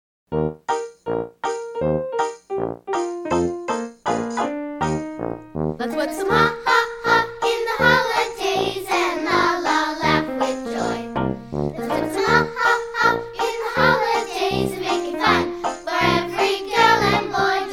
Children's Song Lyrics and Sound Clip